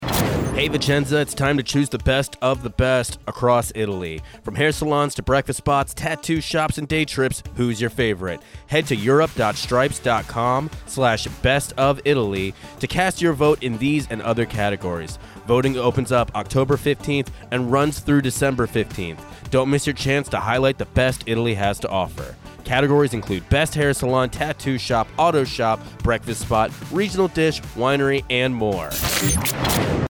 commercial for the best of Italy survey